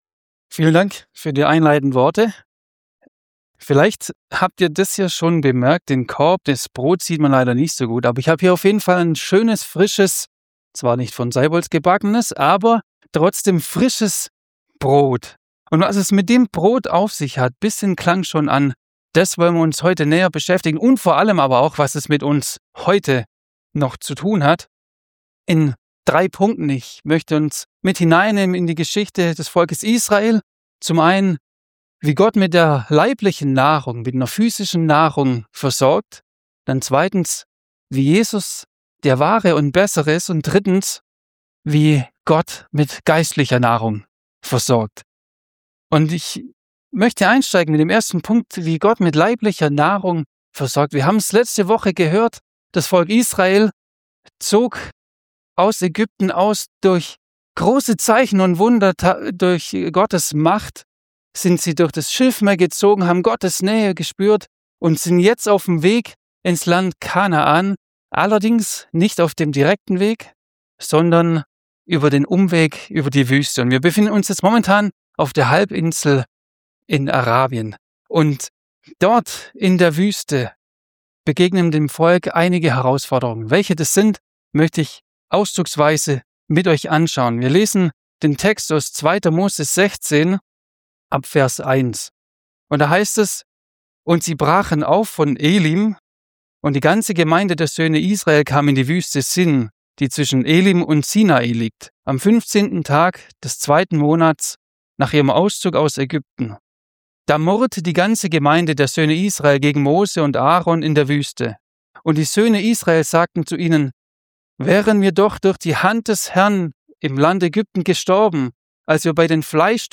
Predigtreihe Exodus - Part 6